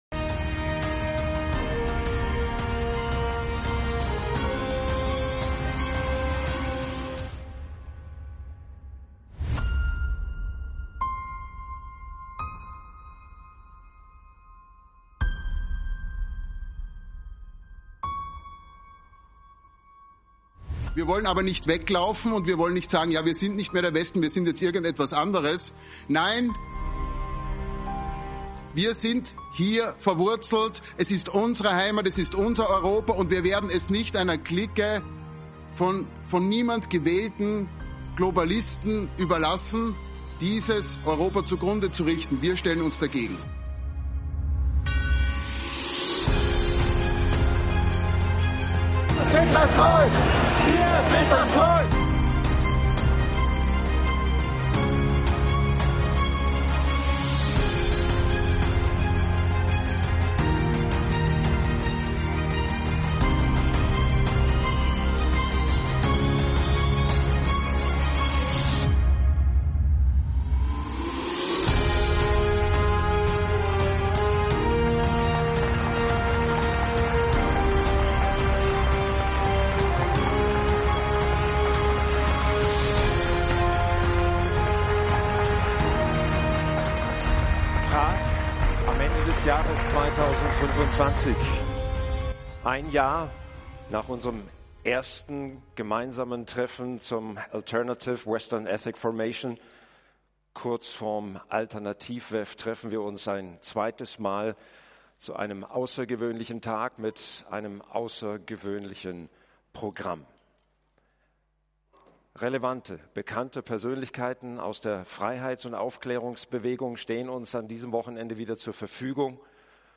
Kämpferischer Auftakt zur A-WEF Jahrestagung 2025 in Prag.